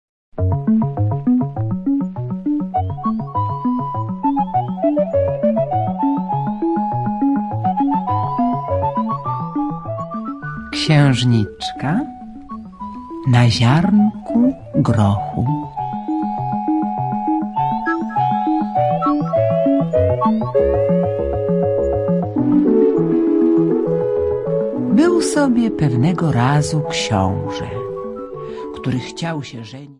Children's Stories by Hans Christian Anderson read in Polish with Accompanying Music.